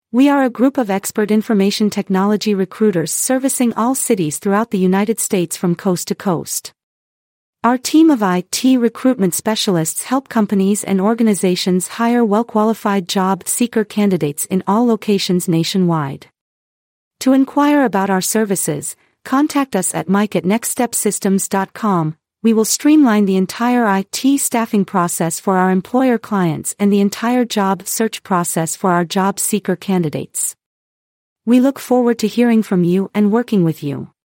Listen to Learn About Our Expert Information Technology Recruiters and IT Staffing Company’s Coverage Area of Cities Using Artificial Intelligence (AI)
Please take a moment to listen to an audio file about our IT staffing company’s coverage area of cities generated by Artificial Intelligence (AI). We are expert information technology recruiters servicing all cities nationwide.